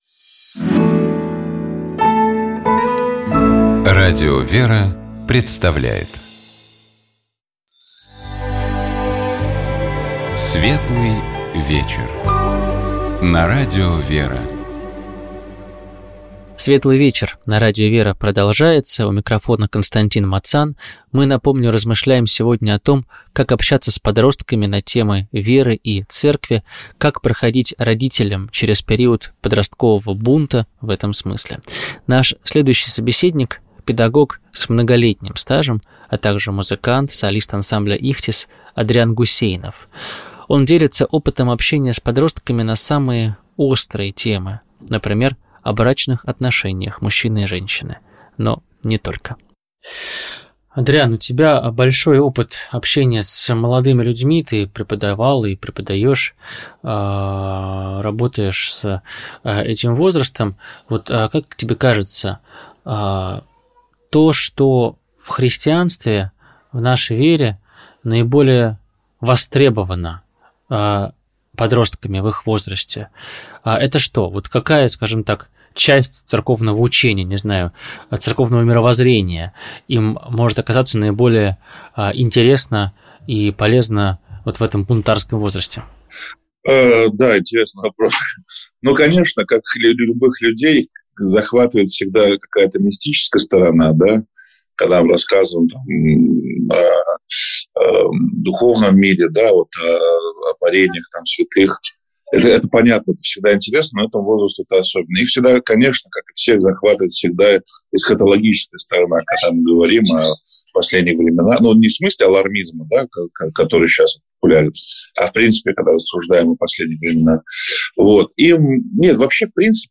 Наша сегодняшняя программа будет не совсем похожа на обычные «Светлые вечера» — в отличие от привычного формата, от часового интервью в студии, мы записали несколько бесед с разными людьми.